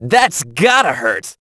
hotshot_kill_03.wav